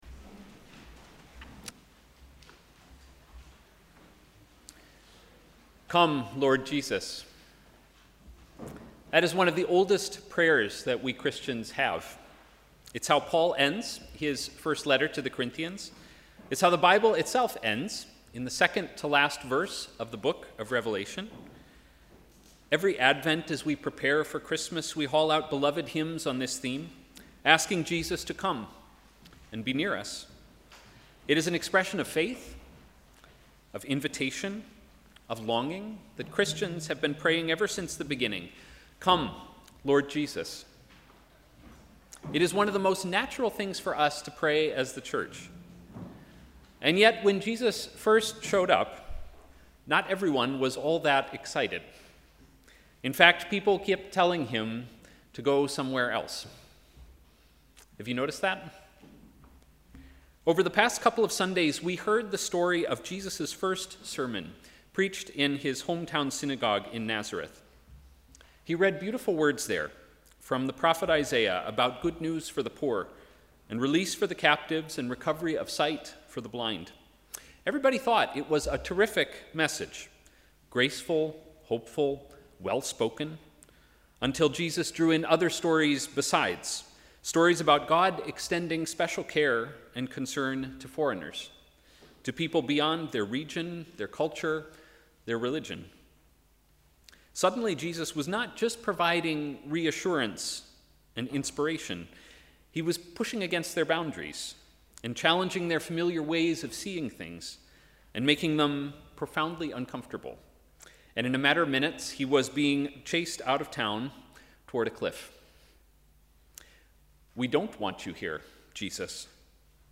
Sermon: ‘Costly grace’